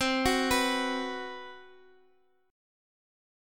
Listen to B5/C strummed